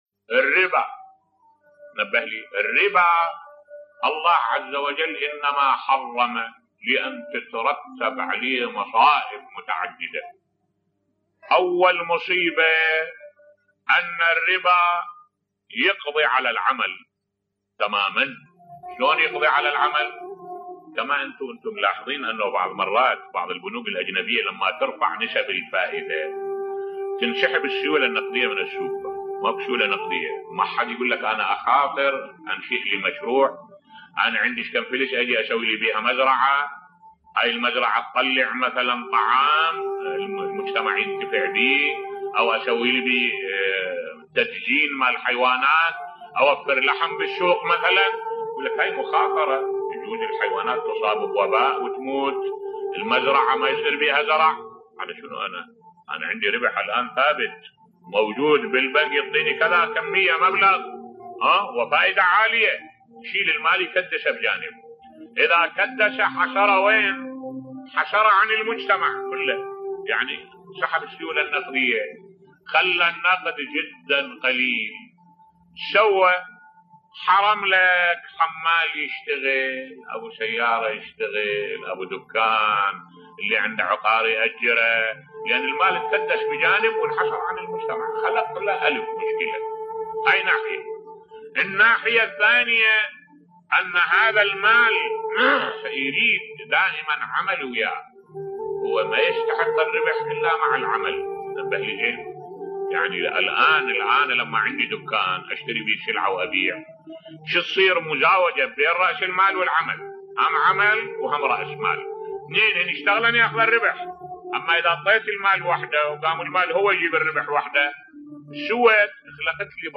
ملف صوتی الربا وأثره في زيادة البطالة بصوت الشيخ الدكتور أحمد الوائلي